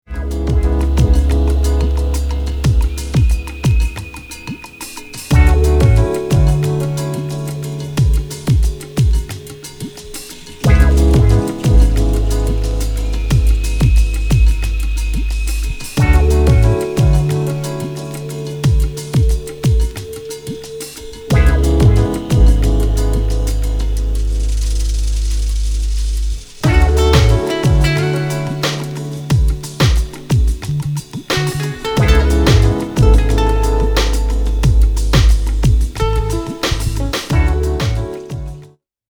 フューチャーした、エスニック・レイドバッキン、大人のラヴァーズ